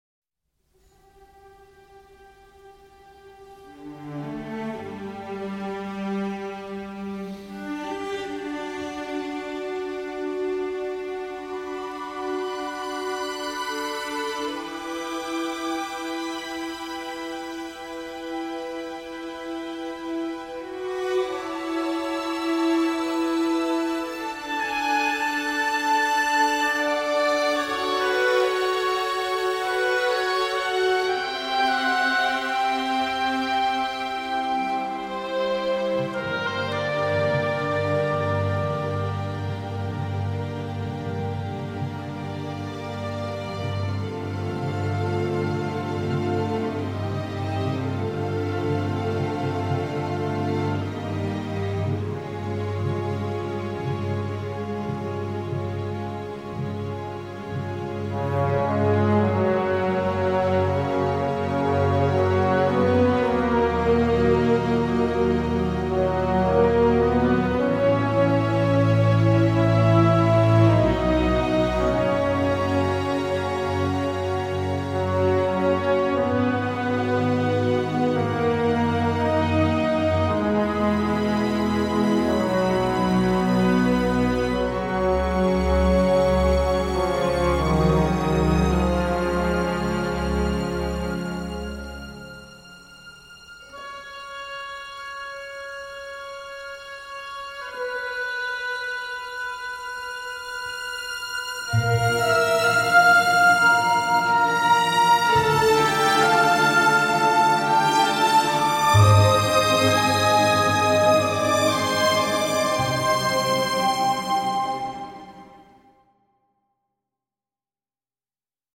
mélange synthés/orchestre
Heureusement que l’orchestre reste prédominant.
malgré les sonorités synthétiques datées